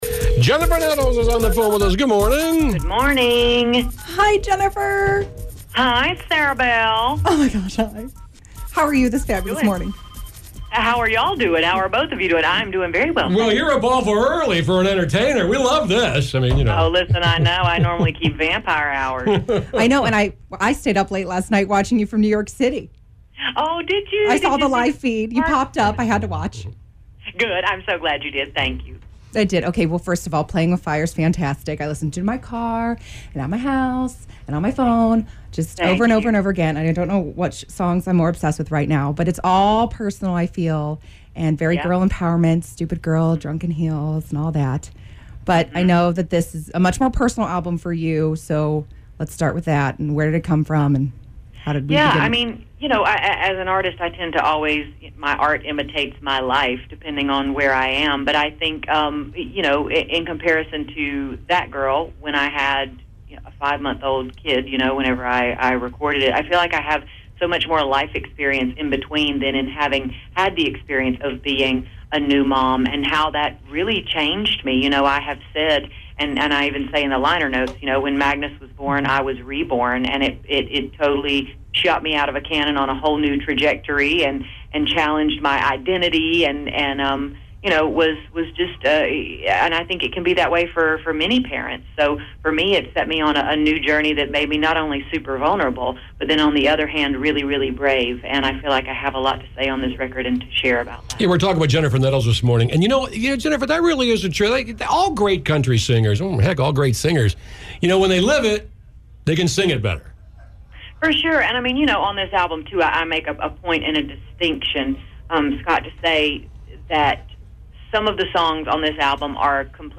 WQMX-Jennifer-Nettles-Interview-51816.mp3